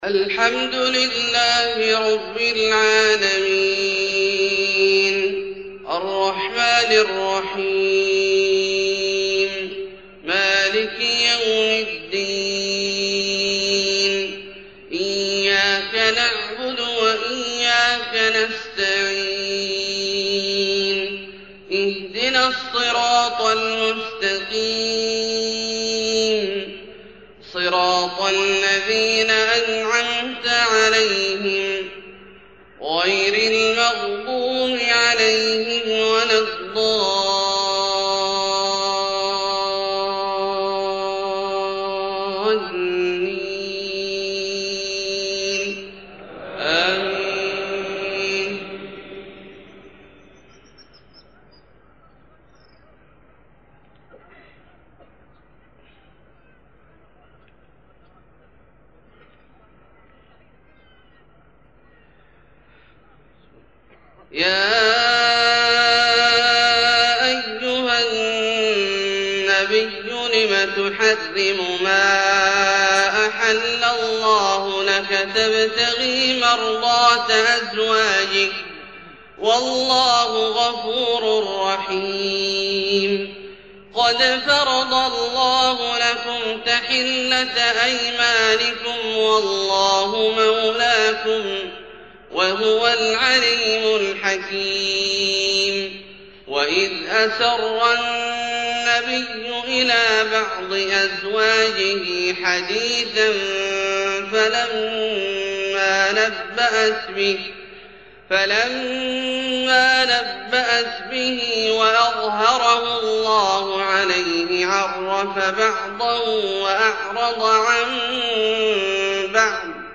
فجر 1-5-1430 سورة التحريم > ١٤٣٠ هـ > الفروض - تلاوات عبدالله الجهني